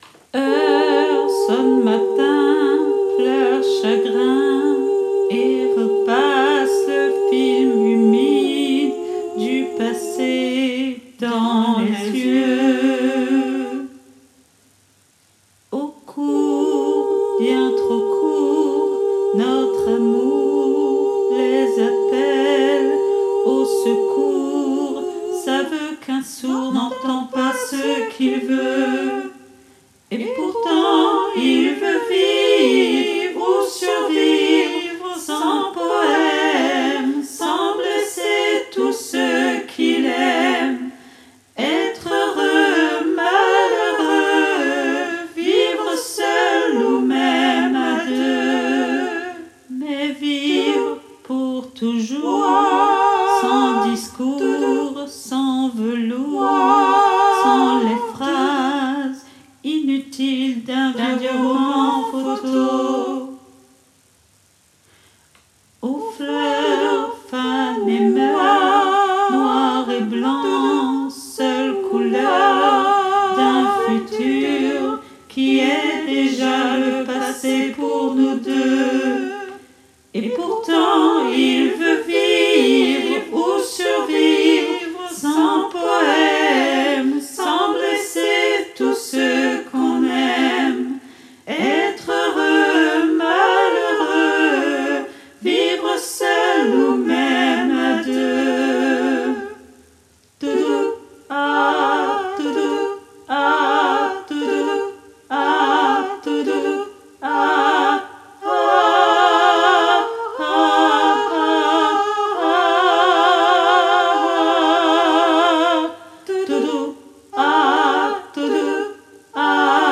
MP3 versions chantées
Tutti